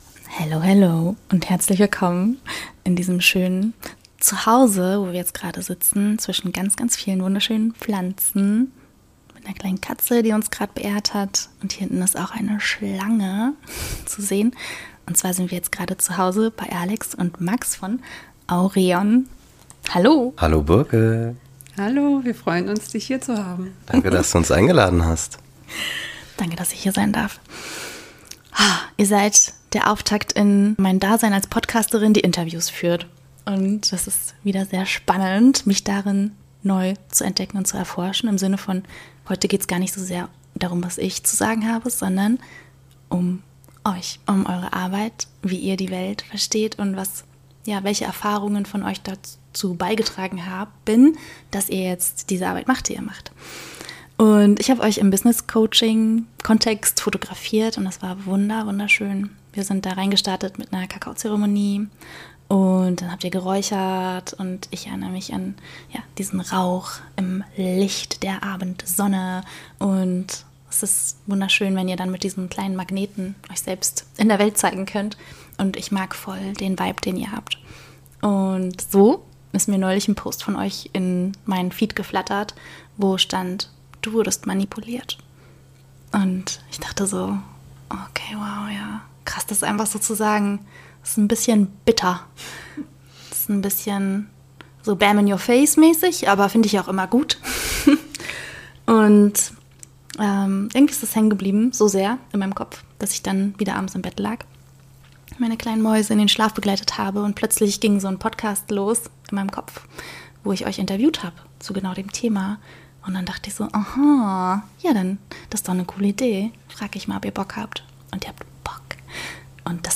Dies ist ein Gespräch über Mut, Systeme, Körperweisheit und das tiefe Bedürfnis, frei und authentisch zu leben.